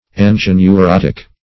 -- An`gi*o*neu*rot"ic ([a^]n`j[i^]*[-o]*n[-u]*r[o^]t"[i^]k), a. [Webster 1913 Suppl.